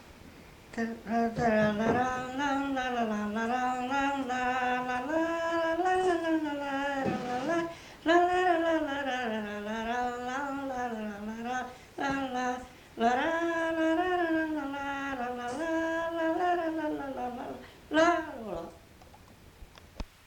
Aire culturelle : Cabardès
Genre : chant
Effectif : 1
Type de voix : voix d'homme
Production du son : fredonné
Danse : bufatièra